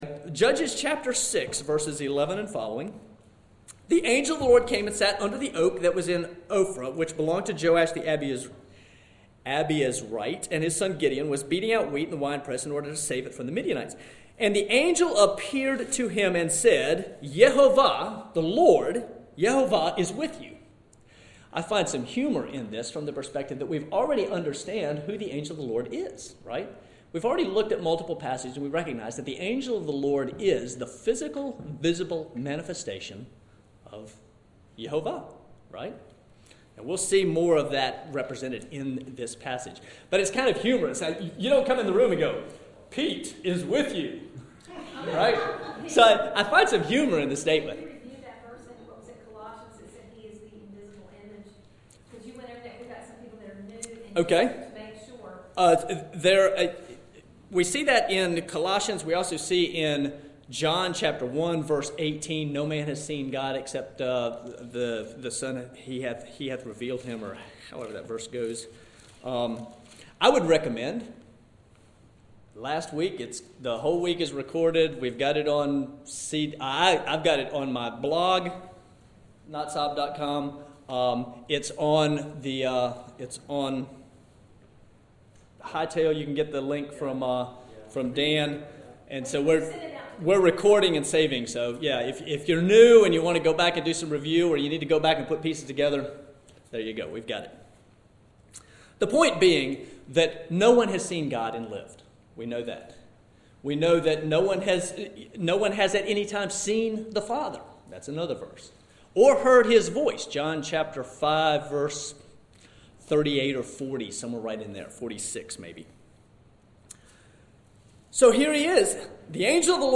This week in Sunday school we had an abbreviated class.
Following is the recording of this week’s lesson as we wrap up the Angel of the Lord segments. I did notice as I went back and listened that I made a couple very small foibles as I felt rushed by the shortened time slot.